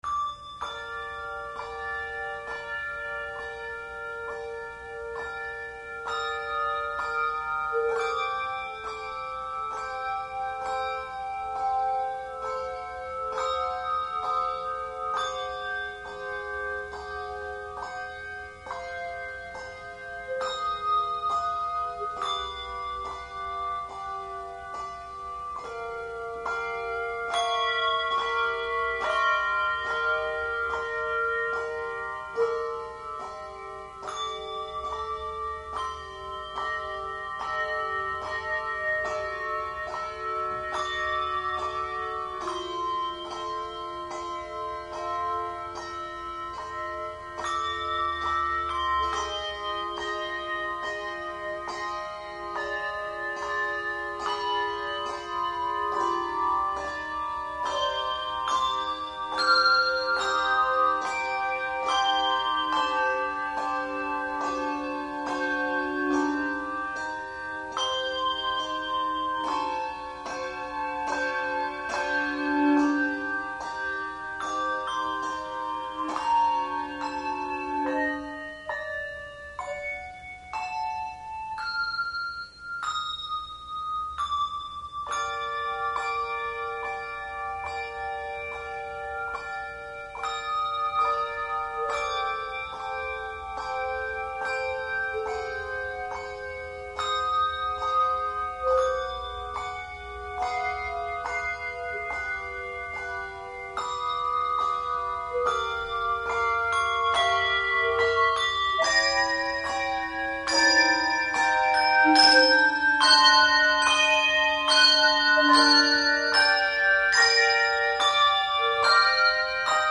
Handbell Quartet
No. Octaves 3 Octaves